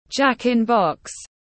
Hình nộm lò xo tiếng anh gọi là jack-in-box, phiên âm tiếng anh đọc là /dʒæk ɪn bɒks/
Jack-in-box /dʒæk ɪn bɒks/